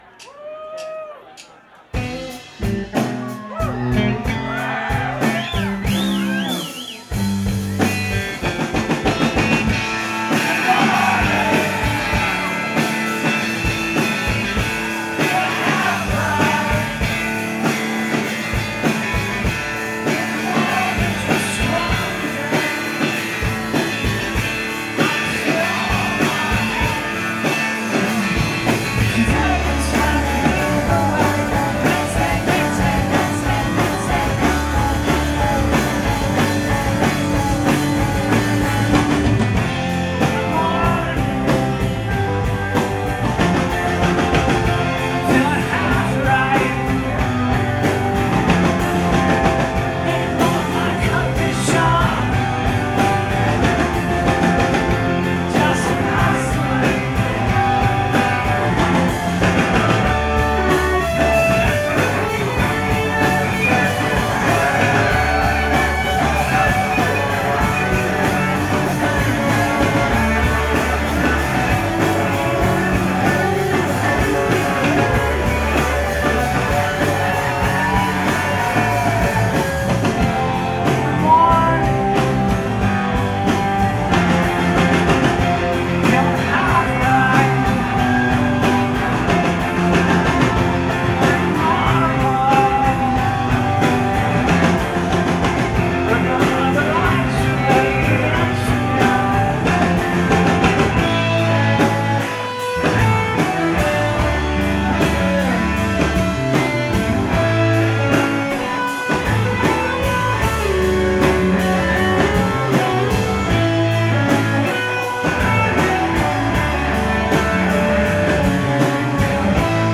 We’re talking joyous, appreciative sing-a-long here, people.
Live at The Wellfleet Beachcomber
in Wellfleet, MA